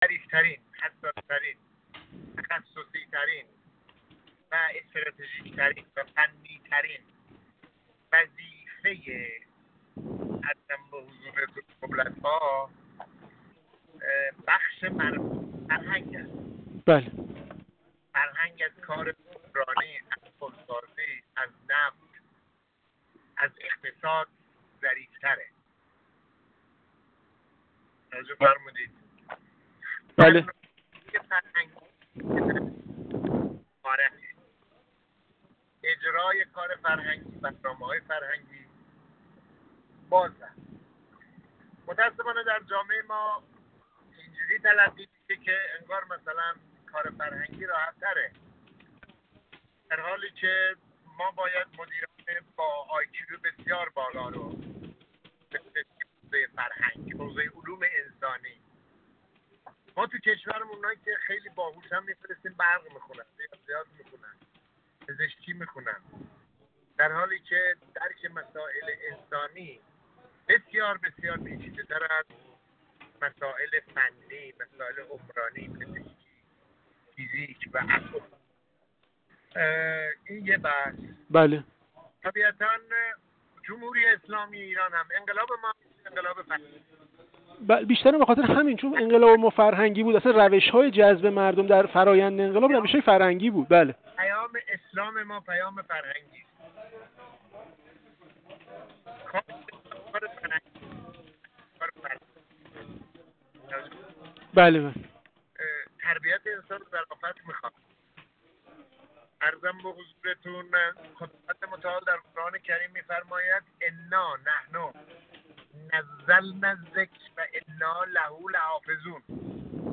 محمود عباس‌زاده مشکینی، عضو کمیسیون امنیت ملی و سیاست خارجی مجلس، در گفت‌وگو با ایکنا درباره الزامات حکمرانی فرهنگی و اجزای مهم مدیریت فرهنگی گفت: ظریف‌ترین، حساس‌ترین، تخصصی‌ترین و استراتژیک‌ترین وظیفه دولت‌ها در حوزه فرهنگ است و این حوزه حتی از اقتصاد و سیاست نیز ظریف‌تر است ولذا مدیریت فرهنگی کار هر کسی نیست و نیازمند مدیری با ویژگی‌های خاص است.